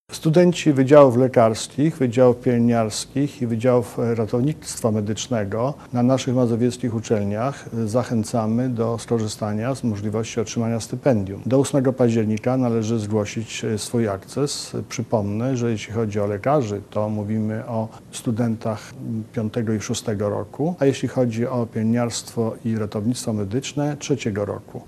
Marszałek województwa mazowieckiego, Adam Struzik dodaje, że stypendium ma zapewnić dobry start przyszłym medykom.